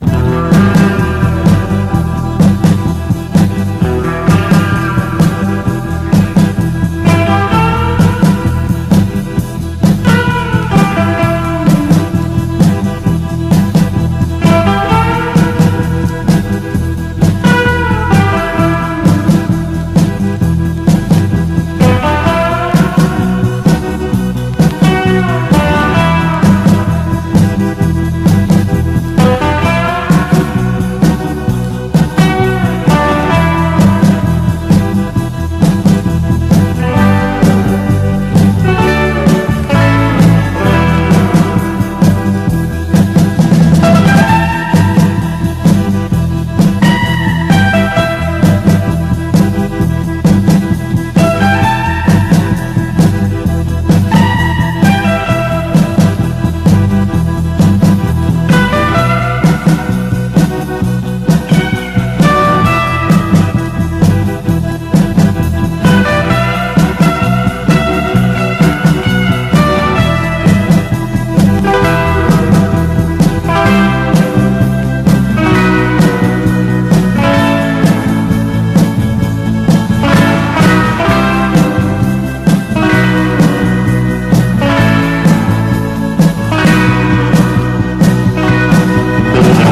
INDIE POP
GUITAR POP / BREAKBEATS